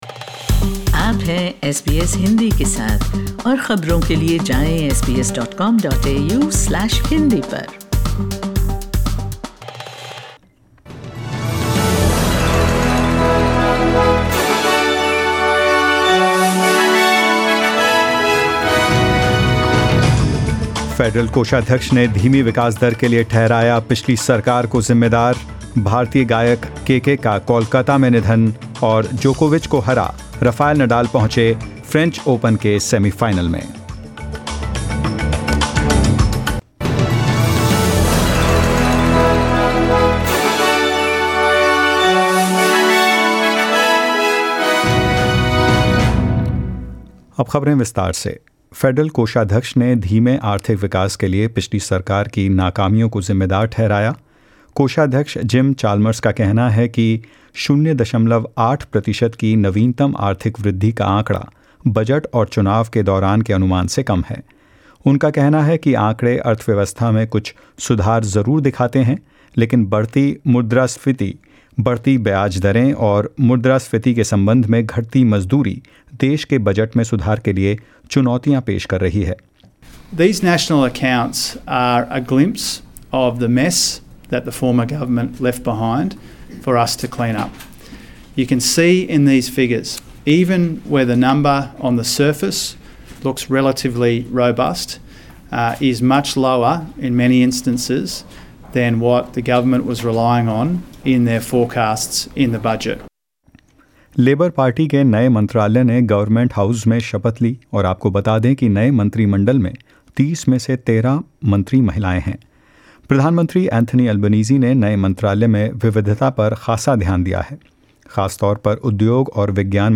In this latest SBS Hindi bulletin: Popular Bollywood singer KK dies at 53; U-S President Joe Biden talks about curbing gun violence with Prime Minister Jacinda Ardern and more